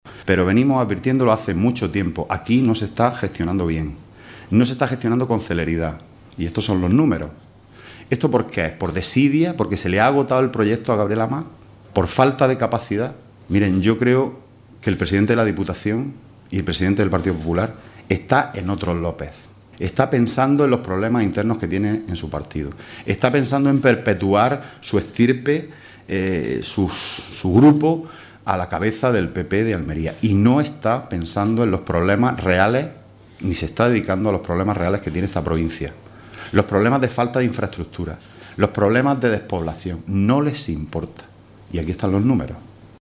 Rueda de prensa que ha ofrecido el portavoz del PSOE en la Diputación de Almería, Juan Antonio Lorenzo, junto a los diputados provinciales Francisco García (izq) y Domingo Ramos